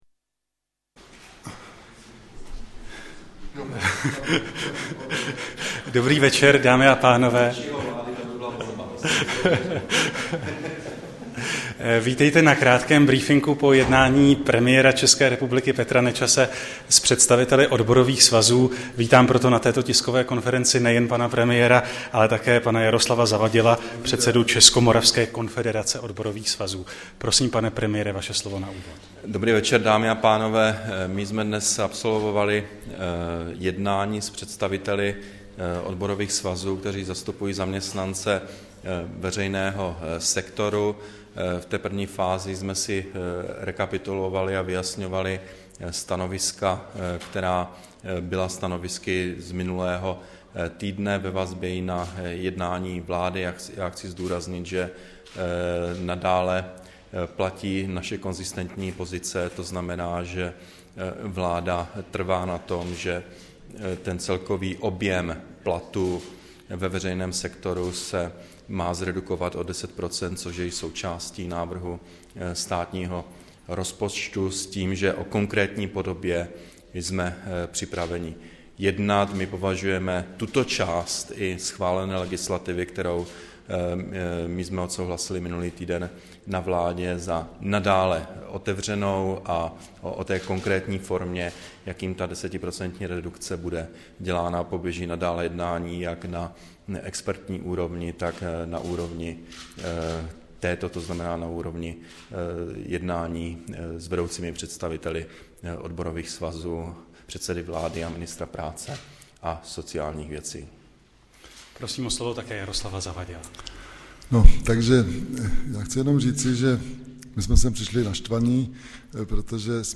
Tiskový brífink předsedy vlády a předsedy ČMKOS, 30. září 2010